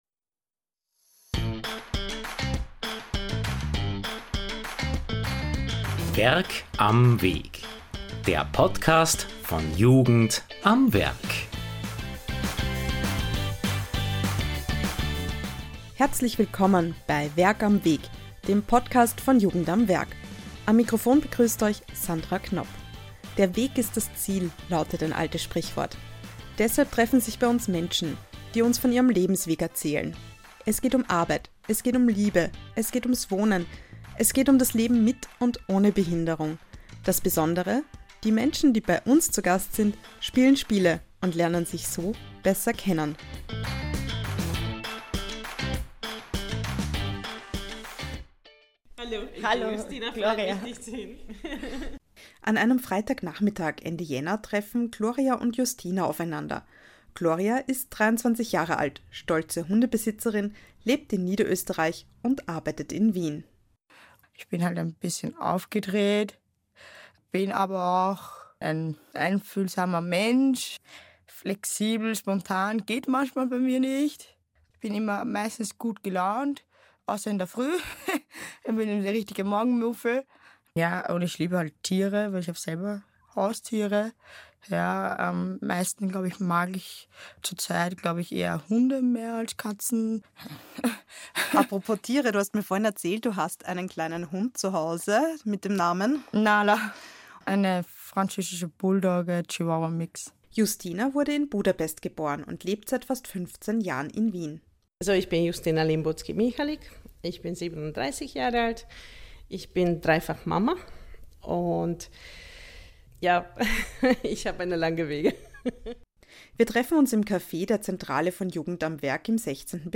Im Podcast von Jugend am Werk erzählen pro Folge zwei Menschen von entscheidenden Momenten und Stationen auf ihrem Lebensweg. Es geht um Arbeit, es geht um Liebe, es geht ums Wohnen.